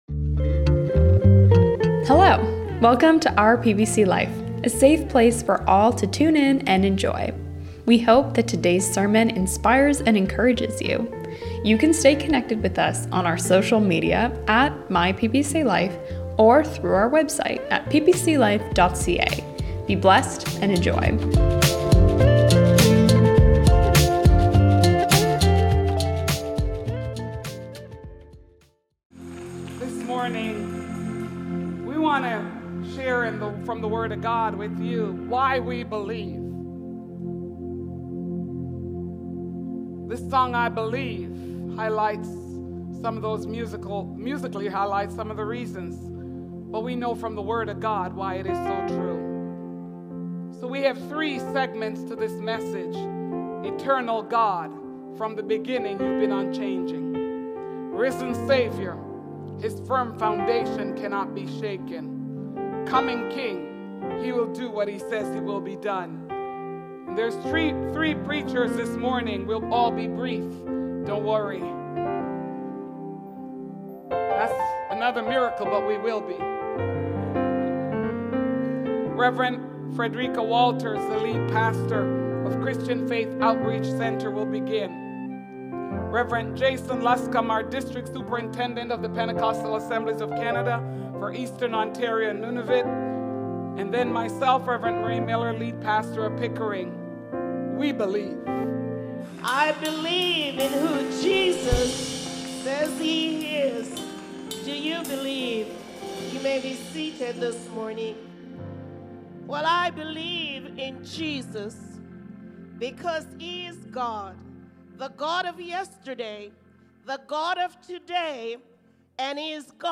Why We Believe! (Easter at The Arena)
Welcome to our Easter Outreach service!